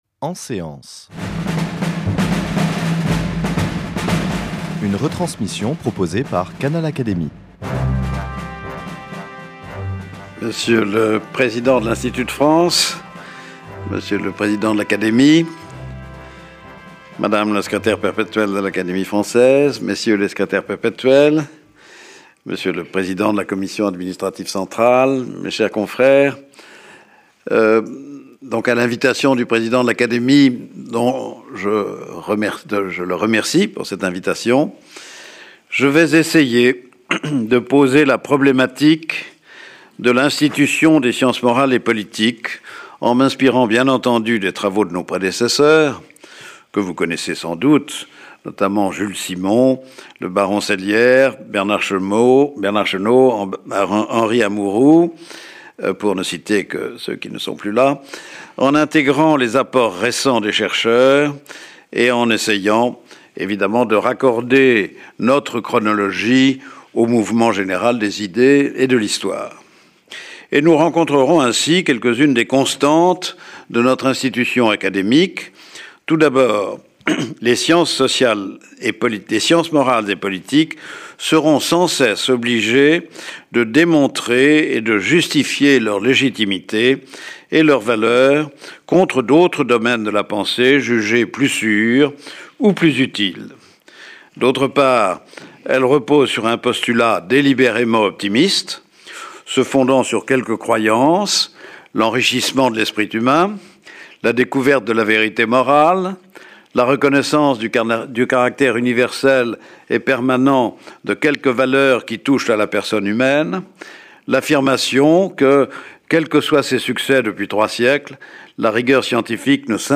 Après avoir présenté une fresque historique très complète de la genèse et de l’évolution de l’Académie, le Chancelier de l’Institut a souligné, à l’occasion de